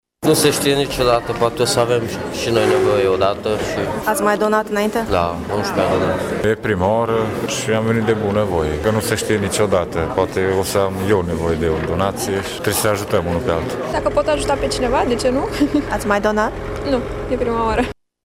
Donatorii au venit la Centrul de Transfuzie, convinși că gestul lor poate salva o viață: